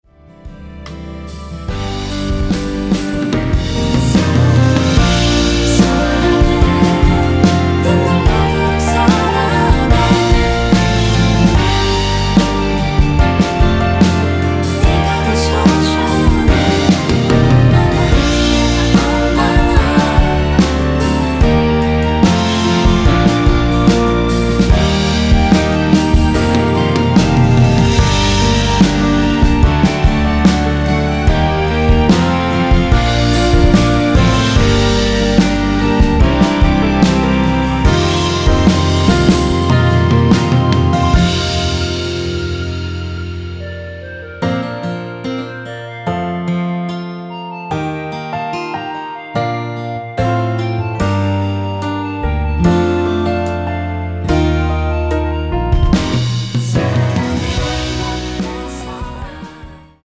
원키 멜로디와 코러스 포함된 MR입니다.(미리듣기 확인)
앞부분30초, 뒷부분30초씩 편집해서 올려 드리고 있습니다.
노래 부르 시는 분의 목소리가 크게 들리며 원곡의 목소리는 코러스 처럼 약하게 들리게 됩니다.